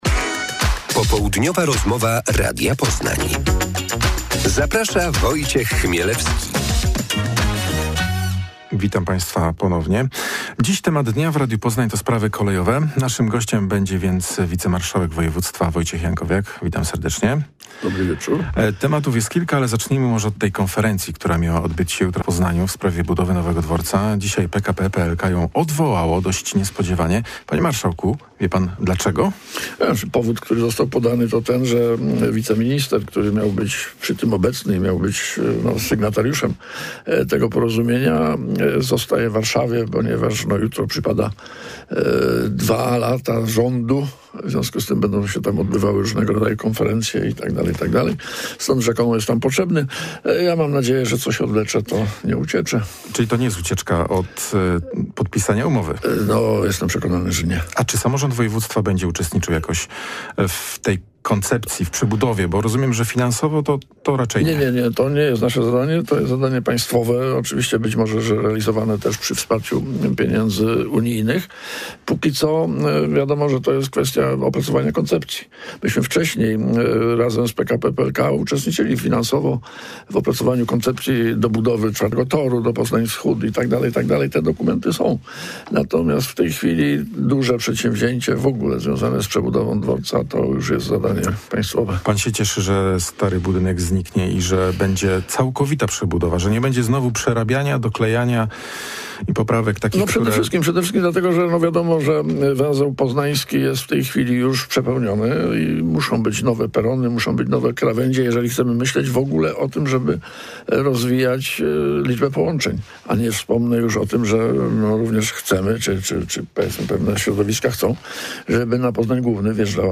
Popołudniowa rozmowa Radia Poznań – Wojciech Jankowiak